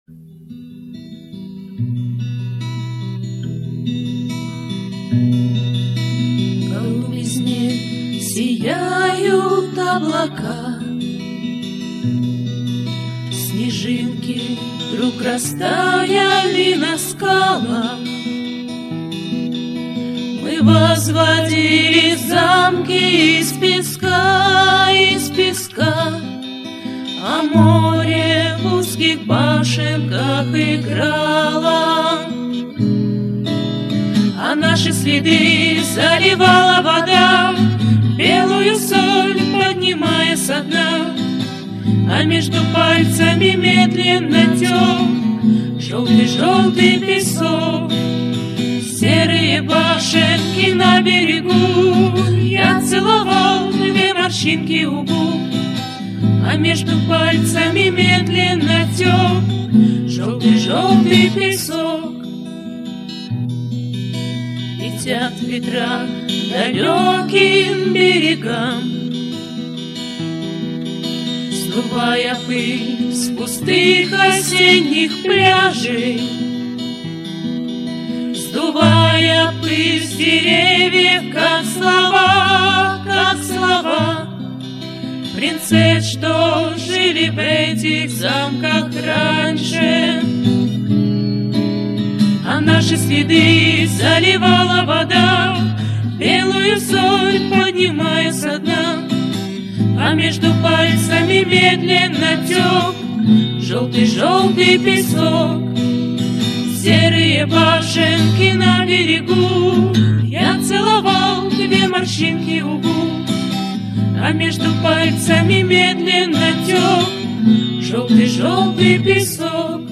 Орлятский вариант песни с неизвестными нам исполнительницами